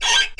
Menu Grow Taller Sound Effect
Download a high-quality menu grow taller sound effect.